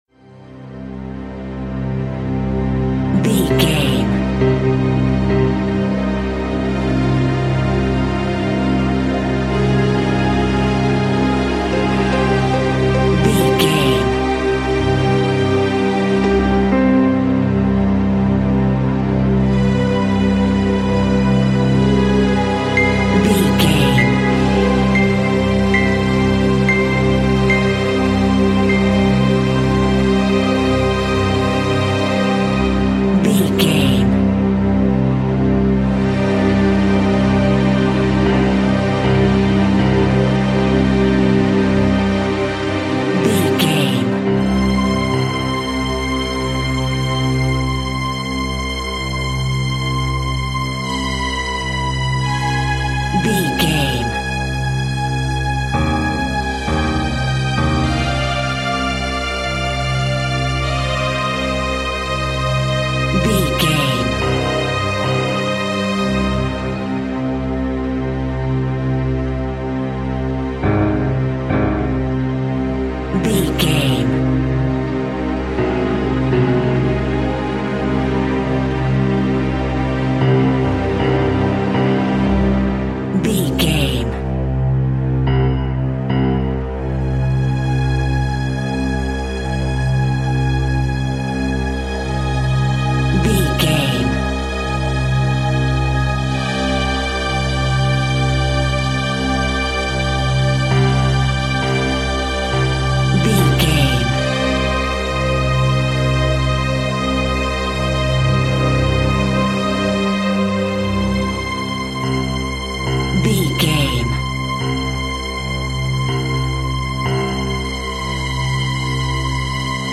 Suspense Music Theme.
Aeolian/Minor
Slow
tension
ominous
dark
eerie
melancholic
strings
piano
synthesiser
pads